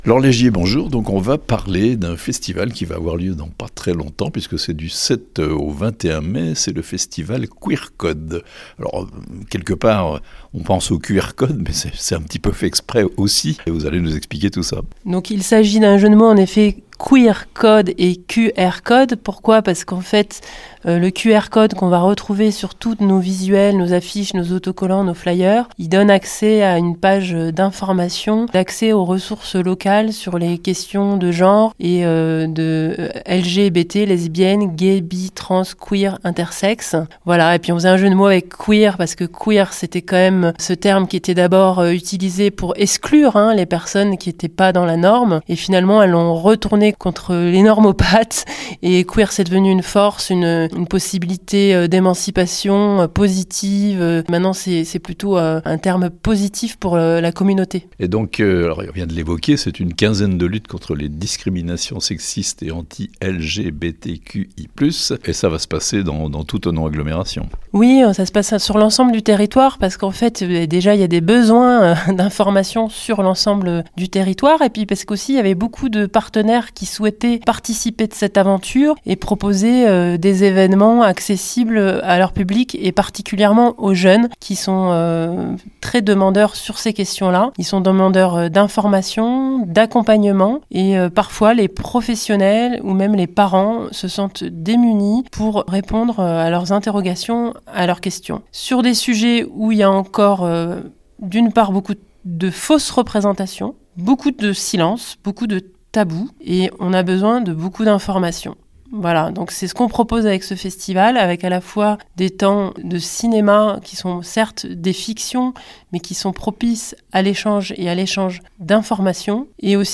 Chablais : un festival de lutte contre les stéréotypes sexistes et les discriminations envers les LGBTQI+ (Interview)